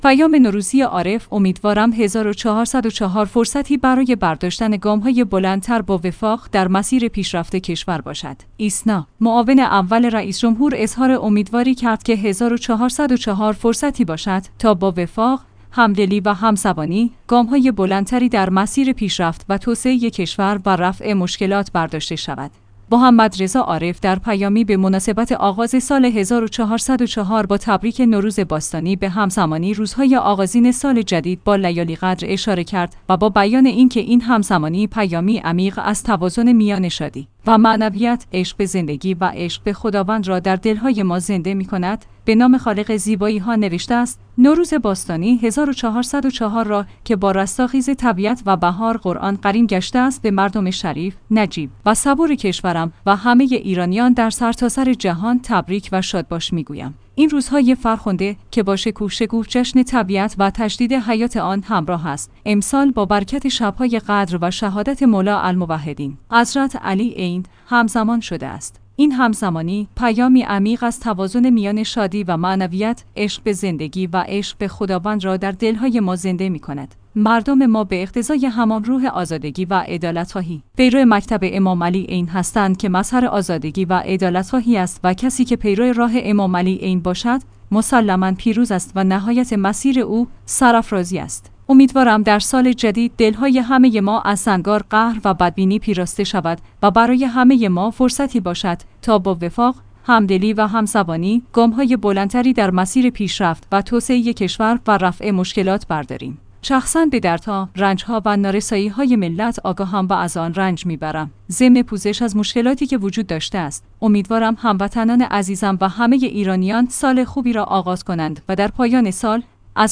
پیام نوروزی عارف؛ امیدوارم ۱۴۰۴ فرصتی برای برداشتن گام‌های بلندتر با وفاق در مسیر پیشرفت کشور باشد